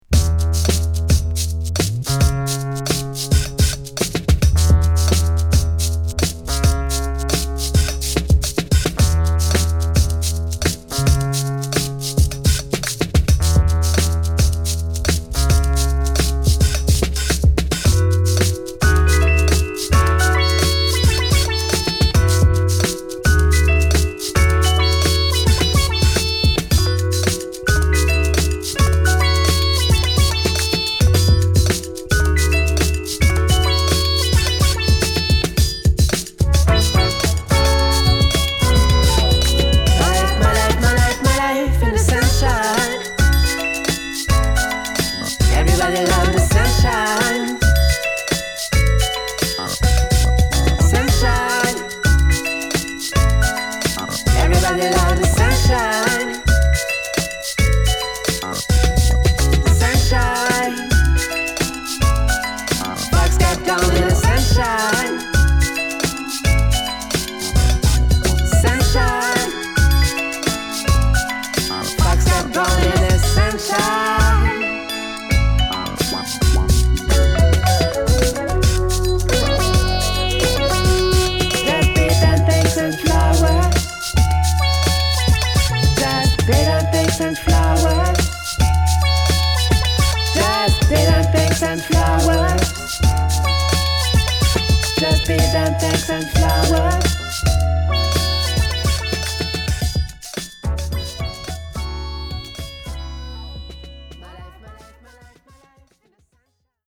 コレが疾走感あるクールなブロークンビーツ／ジャズファンクカヴァーでナイスです。